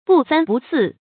注音：ㄅㄨˋ ㄙㄢ ㄅㄨˋ ㄙㄧˋ
不三不四的讀法